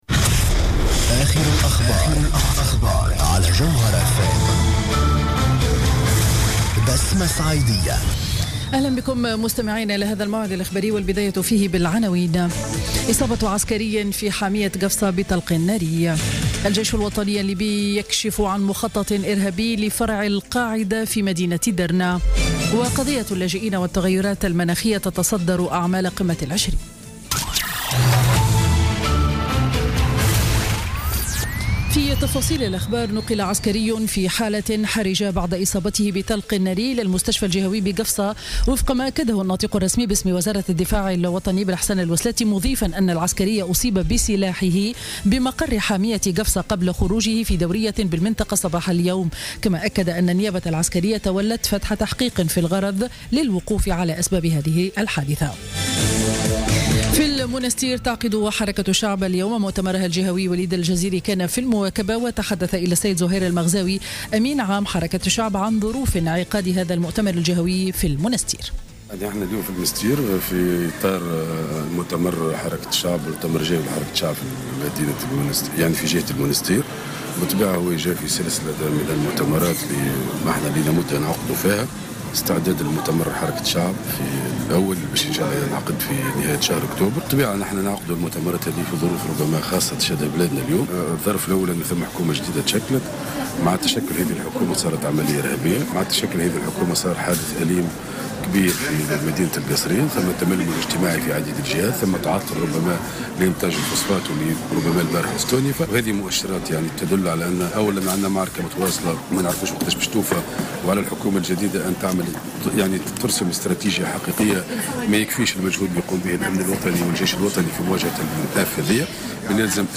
نشرة أخبار منتصف النهار ليوم الأحد 4 سبتمبر 2016